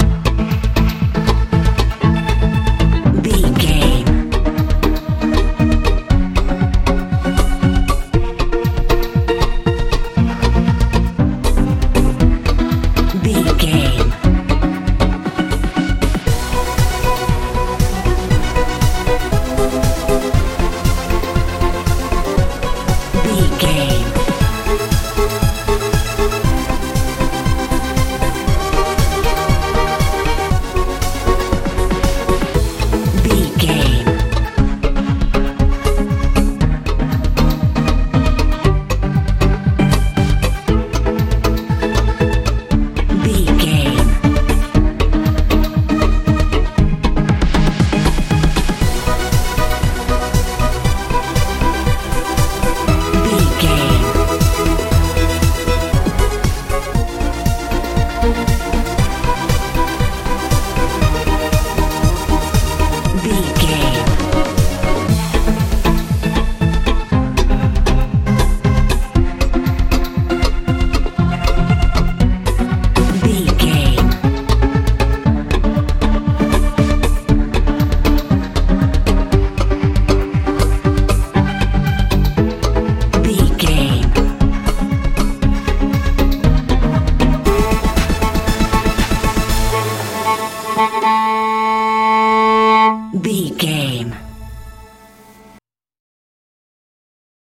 modern dance feel
Ionian/Major
magical
mystical
violin
synthesiser
bass guitar
drums
80s
90s
strange